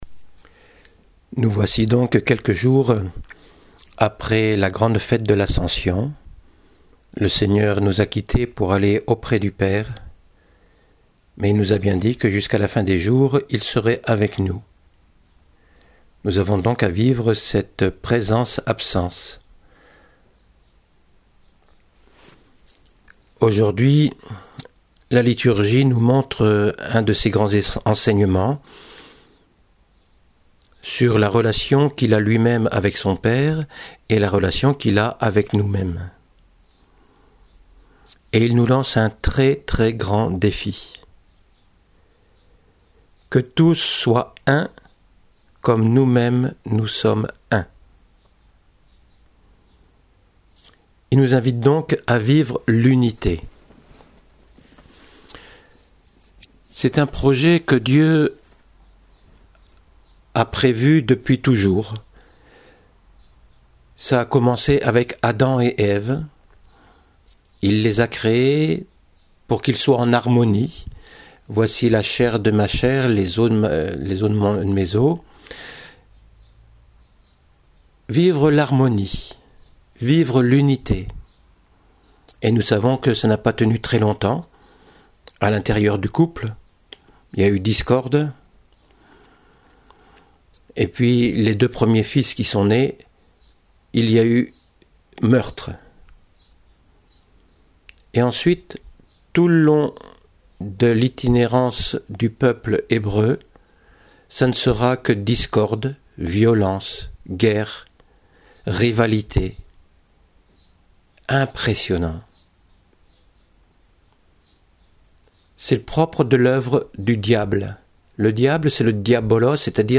homélie du dimanche : Qu’ils soient UN comme nous sommes UN